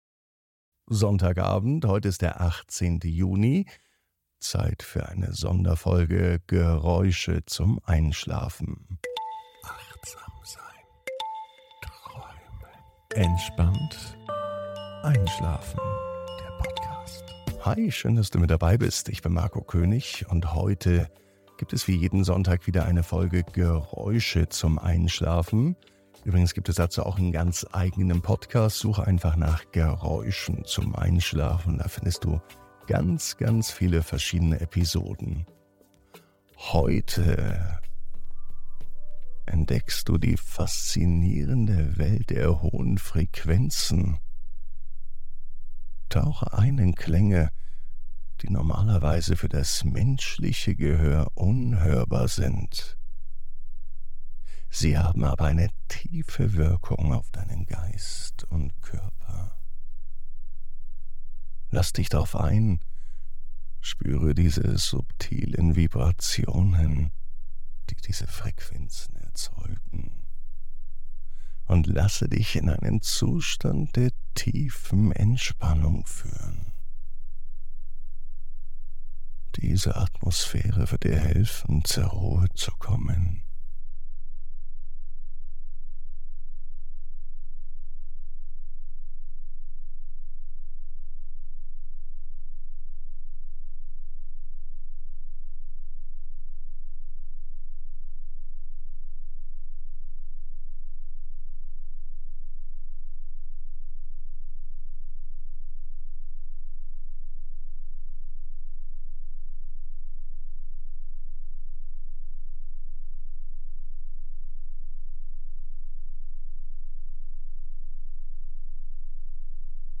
Geräusche zum Einschlafen: Klangreise ins Unhörbare: Entspannung mit Frequenzen ab 10 kHz ~ Entspannt einschlafen - Meditation & Achtsamkeit für die Nacht Podcast
Die hochfrequenten Töne können eine beruhigende und meditative Atmosphäre schaffen, die dir hilft, zur Ruhe zu kommen und einzuschlafen.